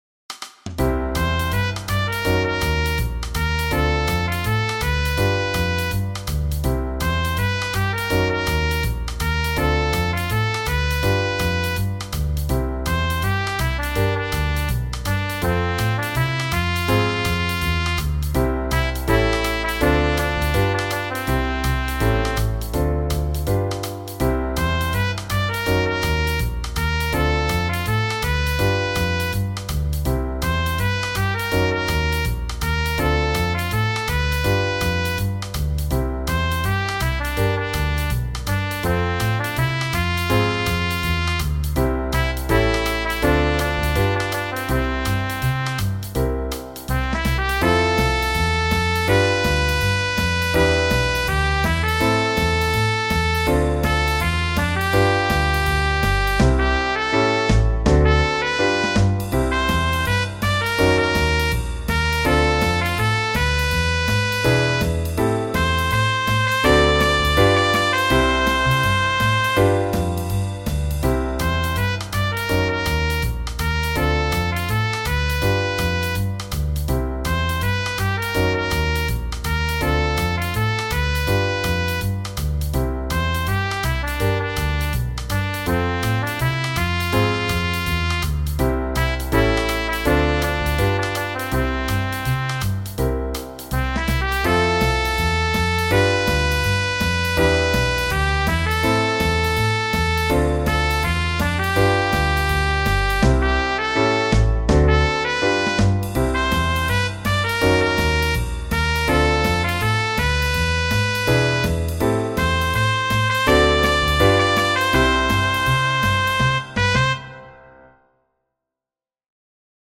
Genere: Jazz